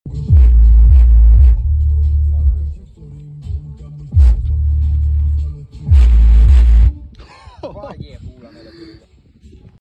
Subwoofer Jbl Stage 1220b Bass Sound Effects Free Download